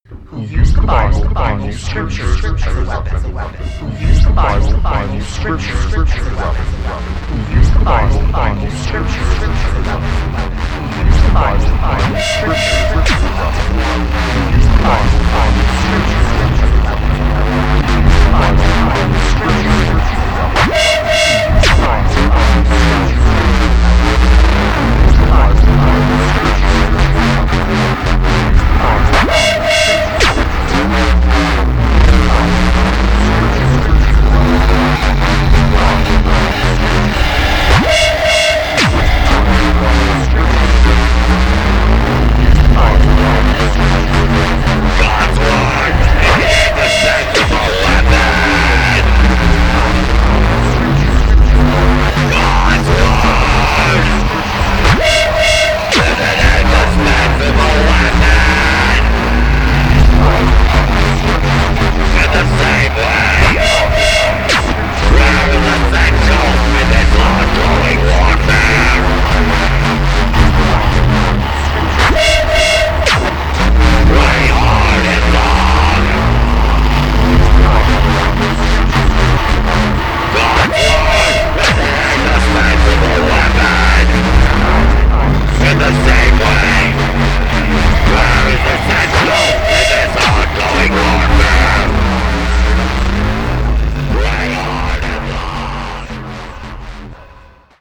Uncompromising Power-Electronics from Oakland / USA.
• Genre: Power Electronics